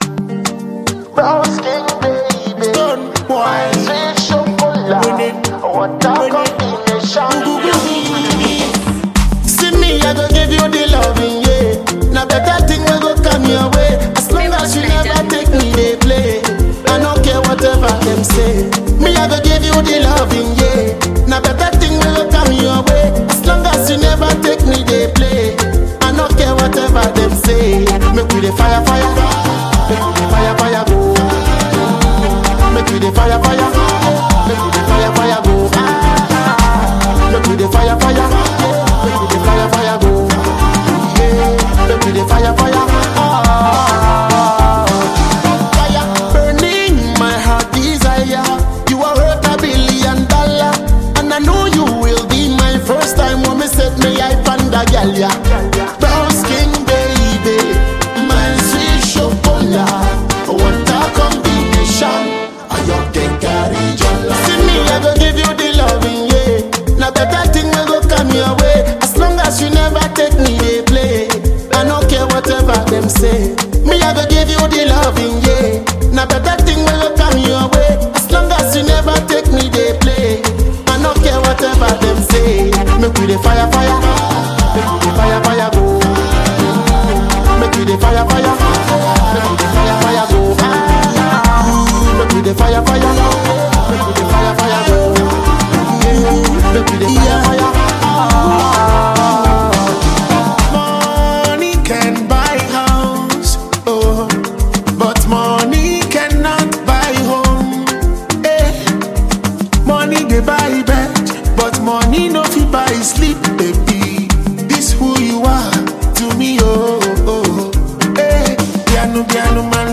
a Ghanaian dancehall performer and entertainer.